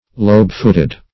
Lobe-footed \Lobe"-foot`ed\, a.